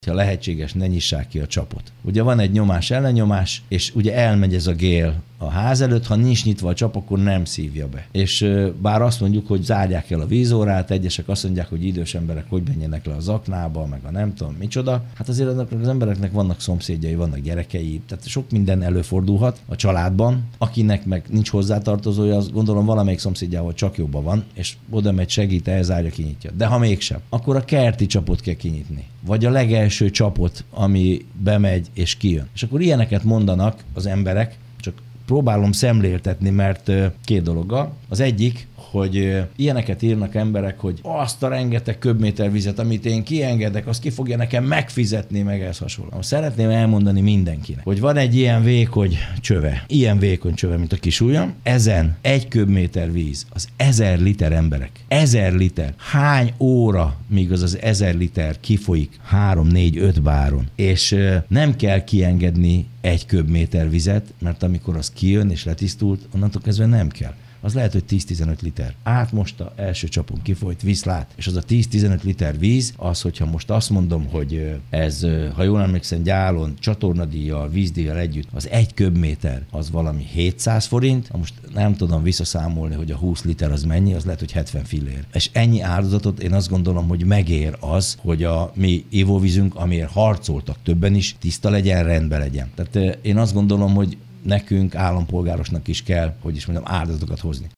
Pápai Mihály polgármestert hallják.
pa0504.mp3